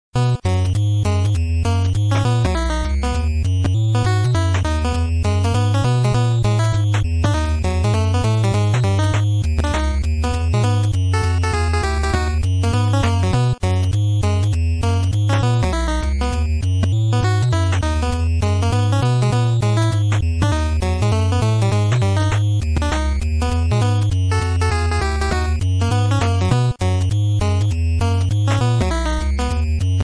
Copyrighted music sample
contribs)applied fade-out and vorbis